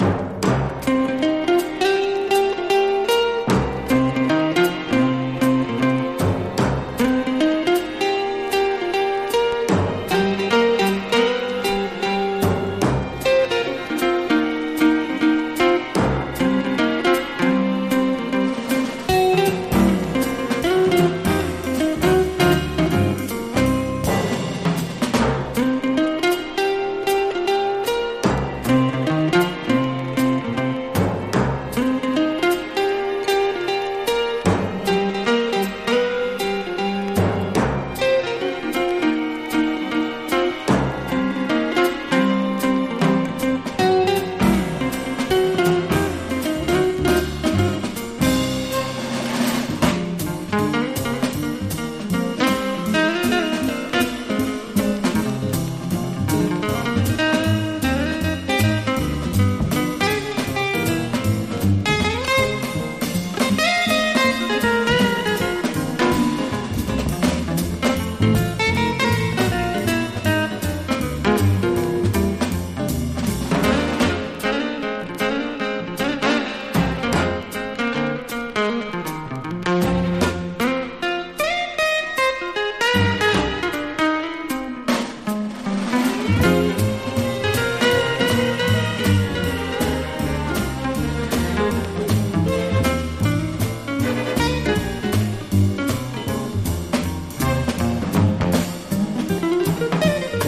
和ジャズ・ギターのトップ二人による1967年の共演盤！